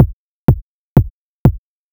edm-kick-25.wav